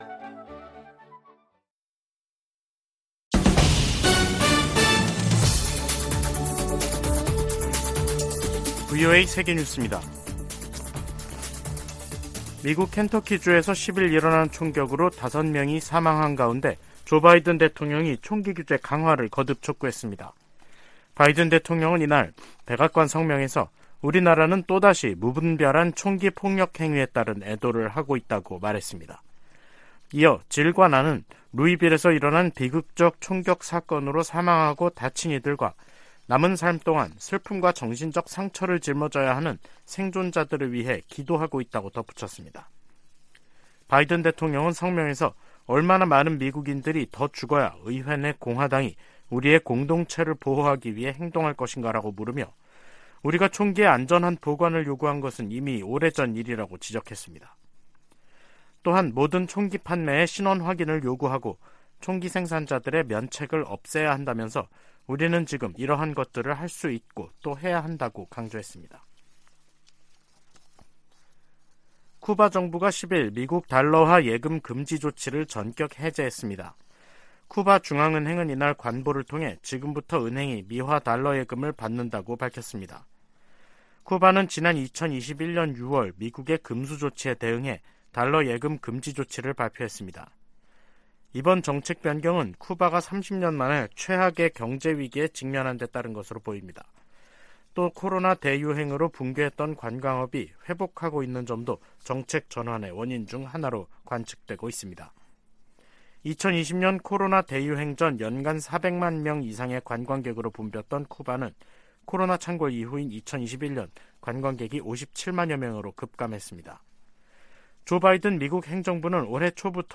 VOA 한국어 간판 뉴스 프로그램 '뉴스 투데이', 2023년 4월 11일 3부 방송입니다. 미국 정부는 정보기관의 한국 국가안보실 도·감청 사안을 심각하게 여기며 정부 차원의 조사가 이뤄지고 있다고 밝혔습니다. 미국 전문가들은 이번 도청 의혹이 두 나라 간 신뢰에 문제가 발생했다고 평가하면서도, 다가오는 미한 정상회담에 큰 영향은 없을 것으로 내다봤습니다. 김정은 북한 국무위원장이 인민군 지휘관들에게 핵 무력을 공세적이고 효과적으로 운용하라고 강조했습니다.